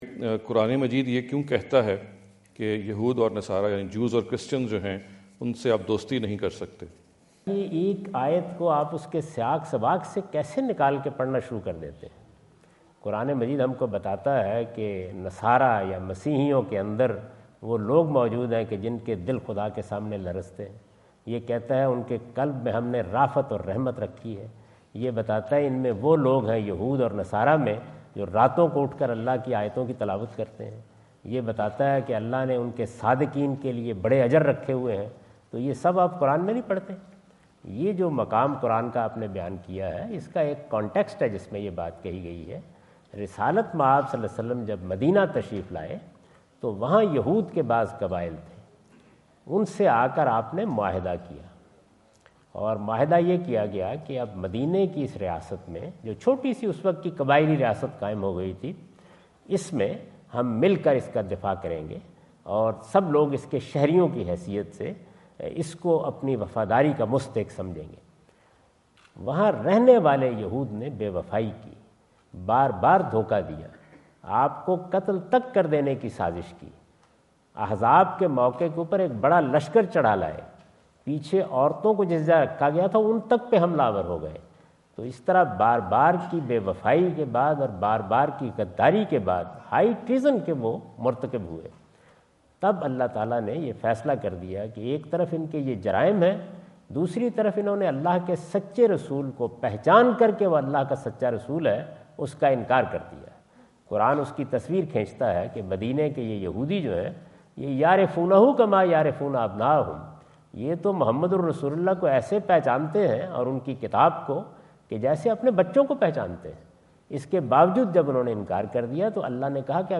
Category: English Subtitled / Questions_Answers /
Javed Ahmad Ghamidi answer the question about "Friendship with Jews and Christians" asked at The University of Houston, Houston Texas on November 05,2017.